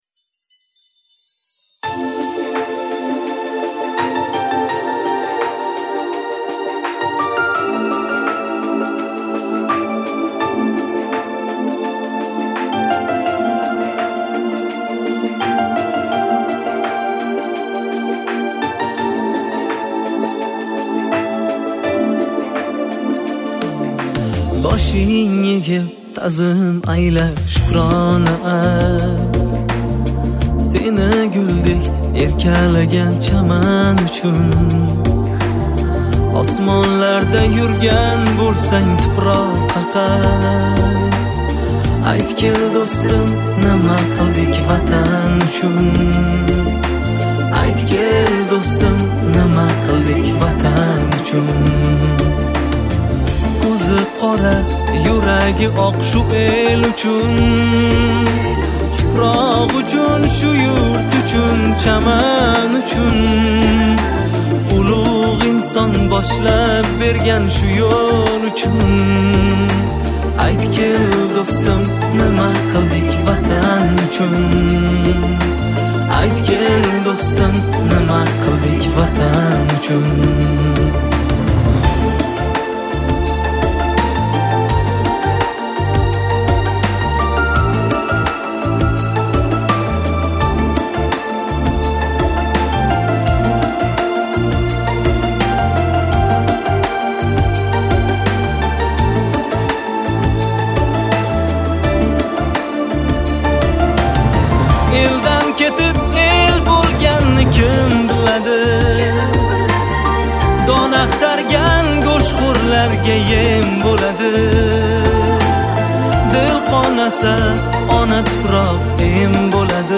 Узбекская музыка